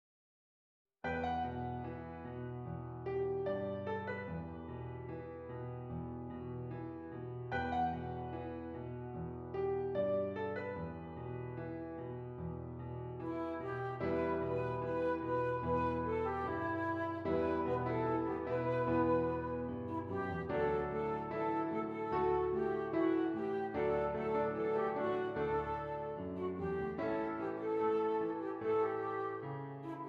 Flute Solo with Piano Accompaniment
E Minor
Moderately slow